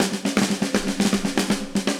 AM_MiliSnareC_120-02.wav